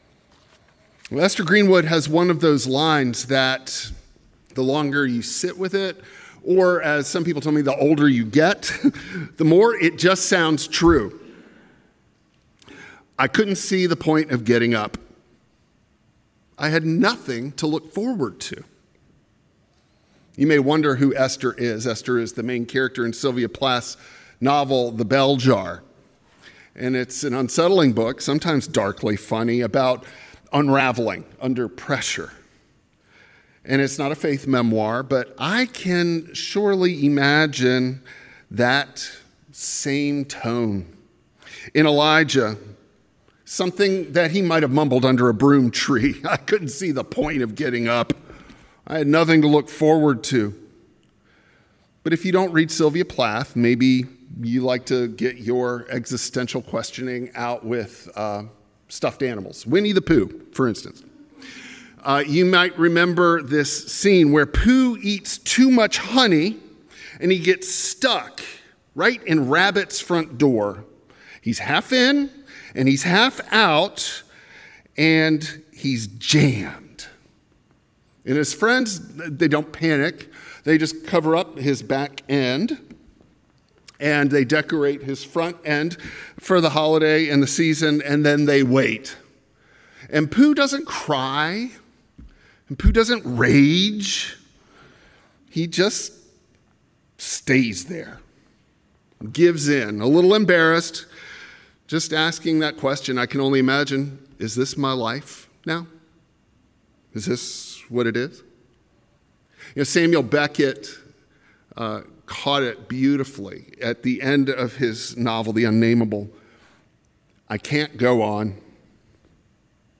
Psalm 42 Service Type: Traditional Service Still here.